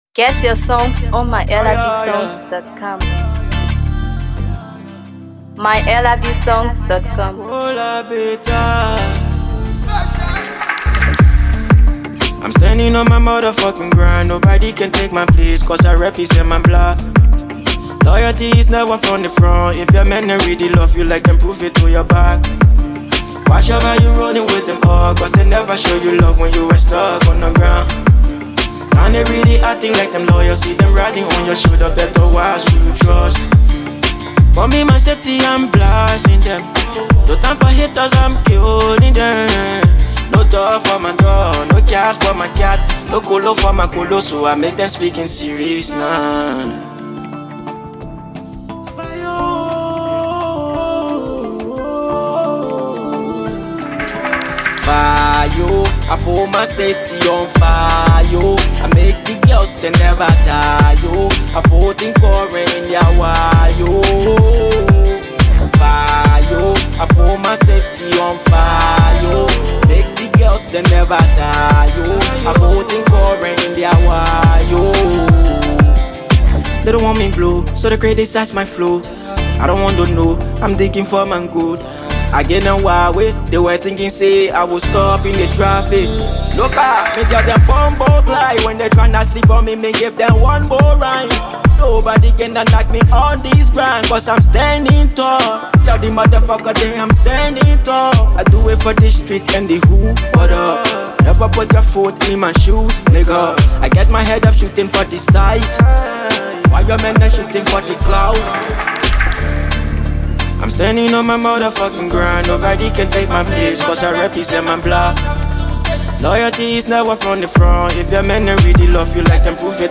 Afro PopDancehallMusic
Dancehall energy meets raw Hip Hop fire